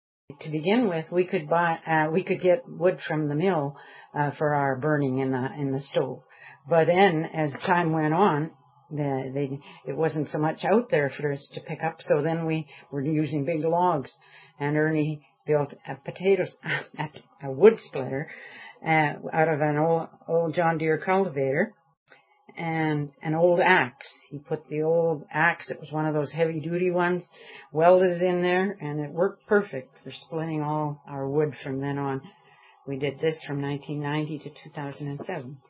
Wood Splitter - Sound Clip